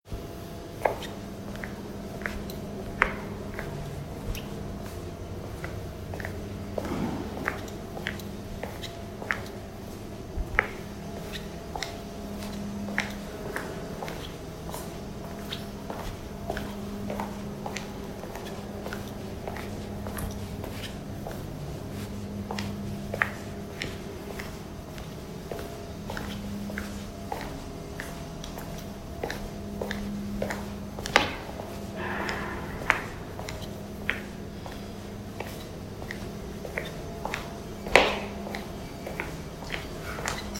발걸음02.mp3